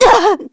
Miruru_L-Vox_Damage_kr_02.wav